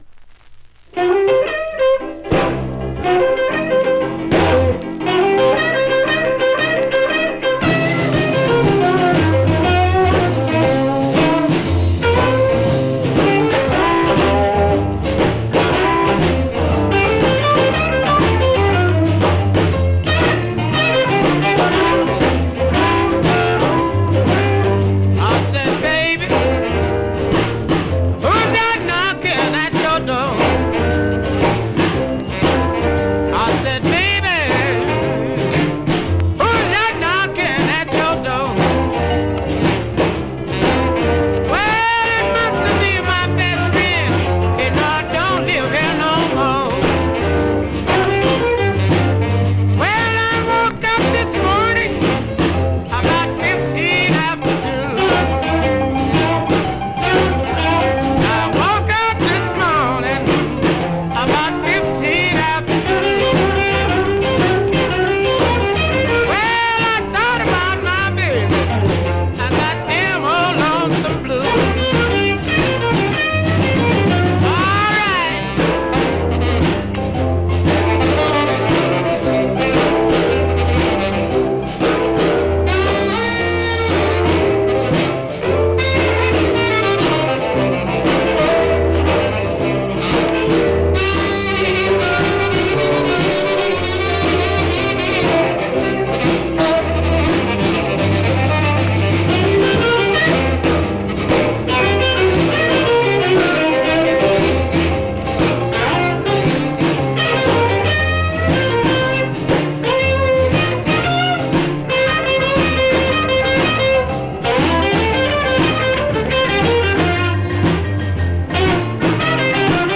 Vinyl has a few light marks plays great .
R&B, MOD, POPCORN